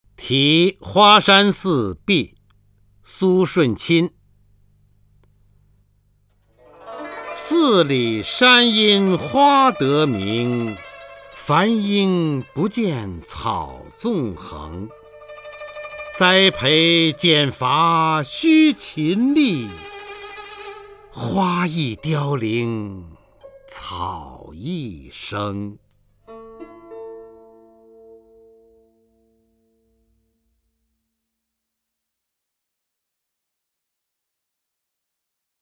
方明朗诵：《题花山寺壁》(（北宋）苏舜钦) （北宋）苏舜钦 名家朗诵欣赏方明 语文PLUS
（北宋）苏舜钦 文选 （北宋）苏舜钦： 方明朗诵：《题花山寺壁》(（北宋）苏舜钦) / 名家朗诵欣赏 方明